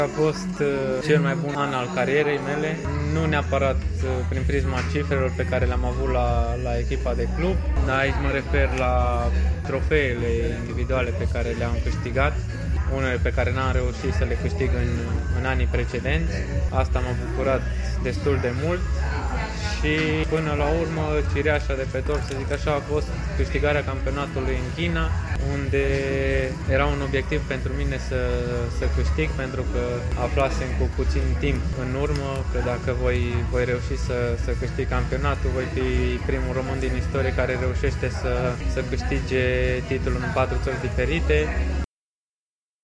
Într-un interviu în exclusivitate pentru Ziarul Unirea, Nicolae Stanciu a făcut o caracterizare a anului 2022 din punctul său de vedere.